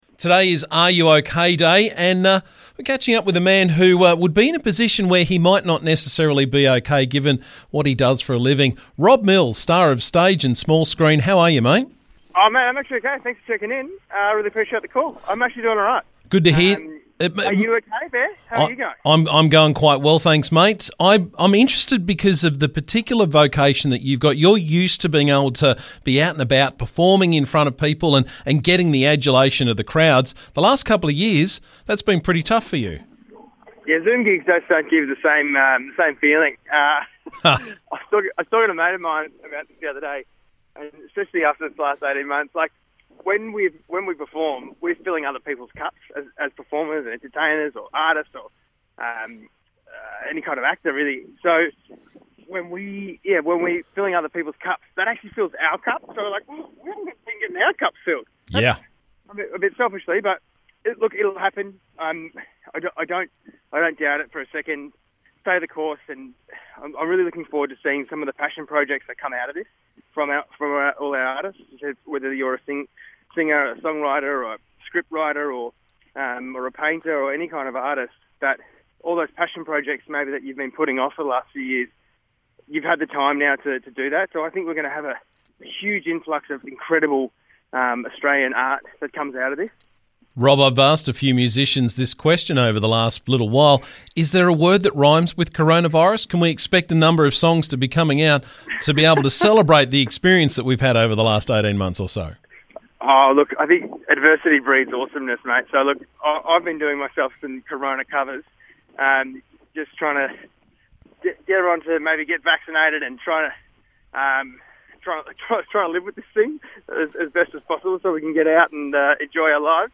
Today is RUOK Day and singer, performer and actor Rob Mills joined was in to discuss talking about it when we're not okay.